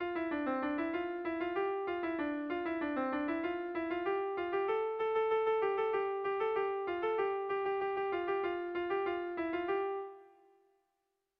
Sentimenduzkoa
Zortziko txikia (hg) / Lau puntuko txikia (ip)
A1A2BD..